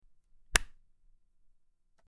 Slap
Slap.mp3